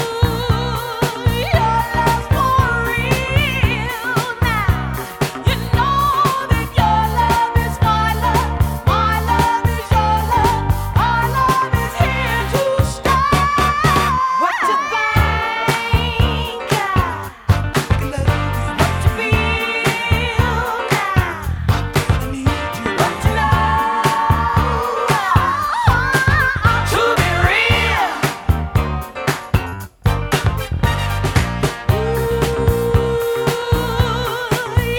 Жанр: R&b / Рок / Соул